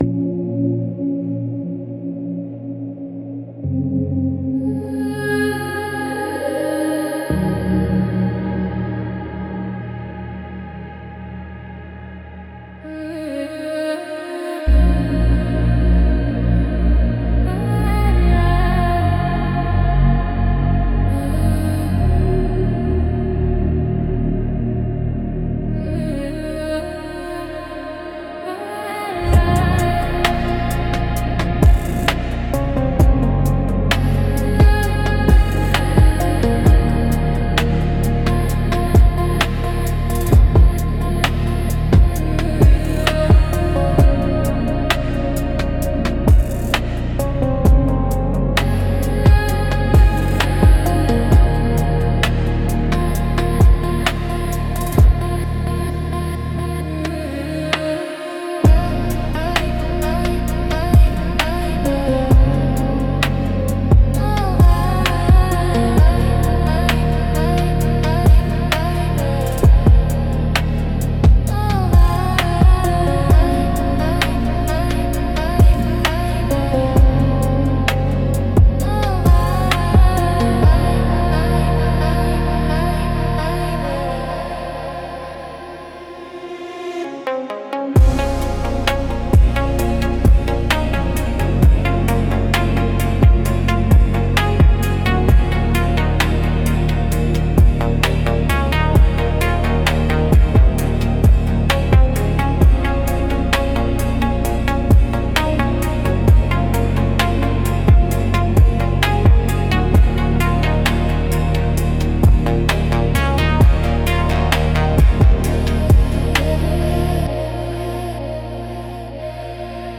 Instrumental - Slow Unraveling of Light 3.49